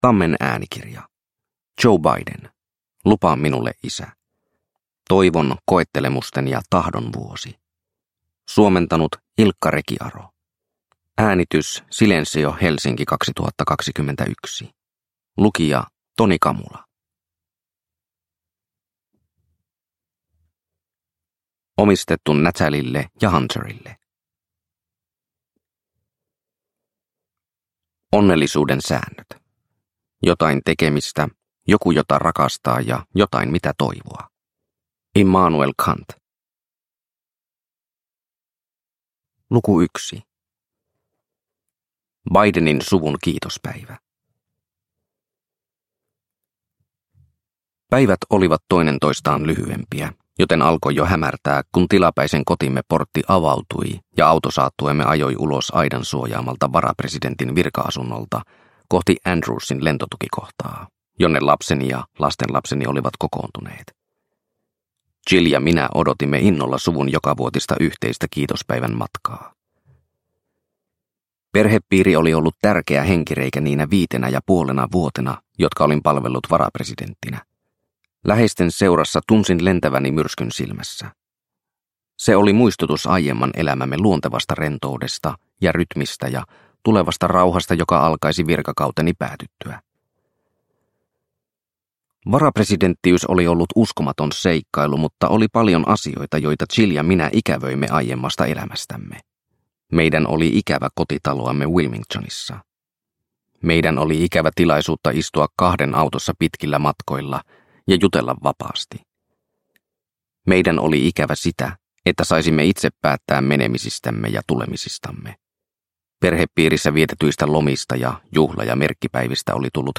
Lupaa minulle, isä – Ljudbok – Laddas ner